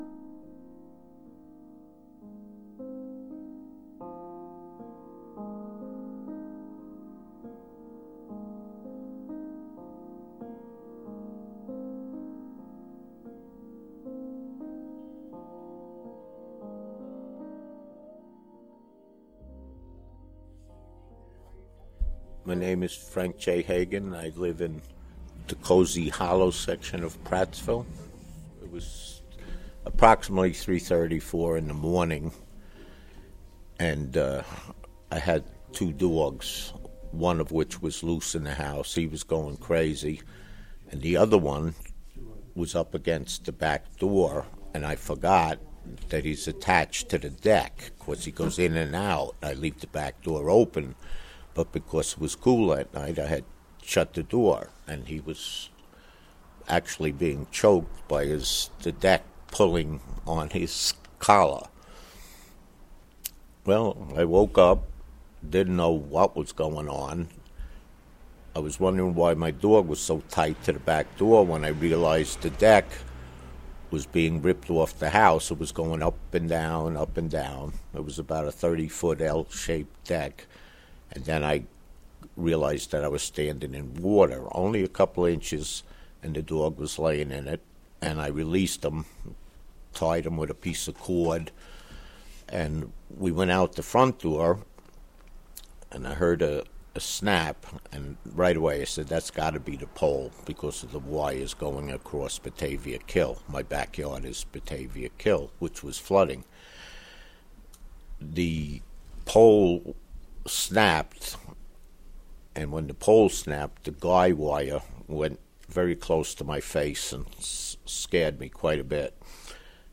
Residents of Prattsville remember the first moments of August 28th, 2011, when flooding from Hurricane Irene devastated the mountaintop community.